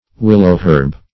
Willow-herb \Wil"low-herb`\, n. (Bot.)